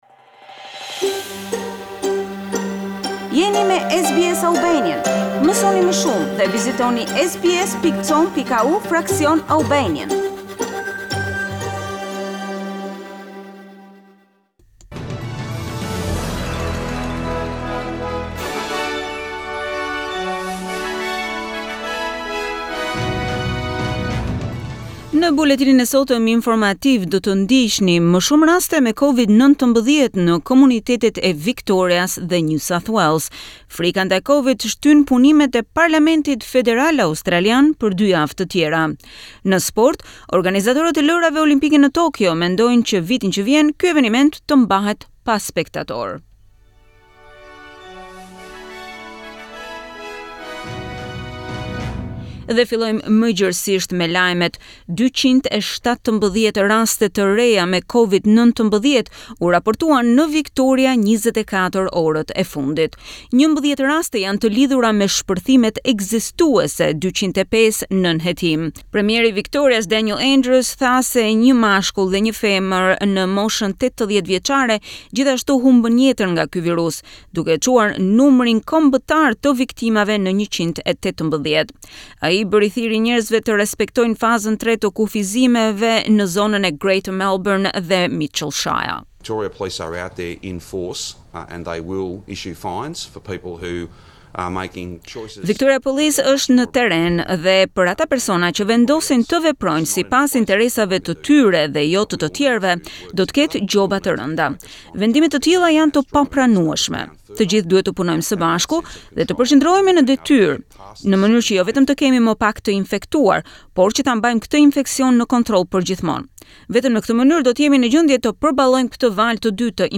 SBS News Bulletin - 18 July 2020